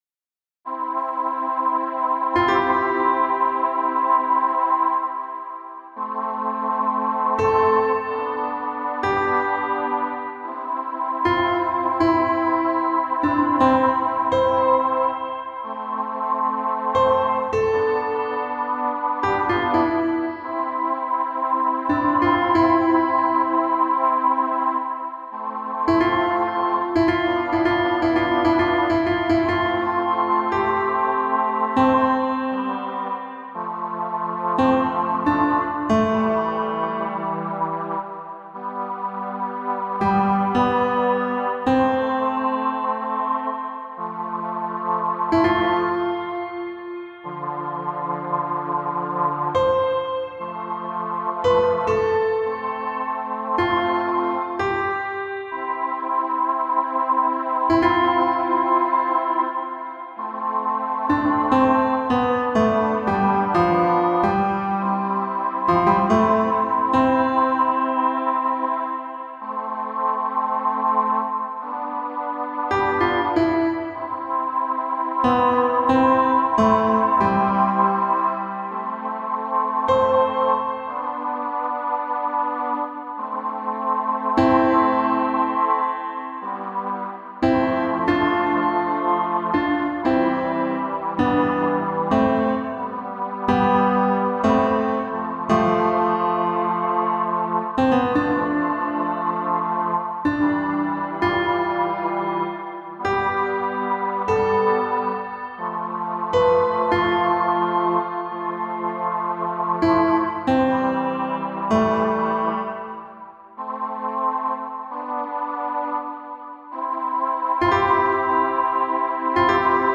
Recorded at home in Harlem, NYC April 27, 2025
Solina, piano Quarry Stereo